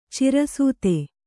♪ cira sūte